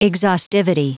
Prononciation du mot : exhaustivity